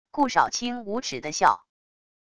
顾少卿无耻的笑wav音频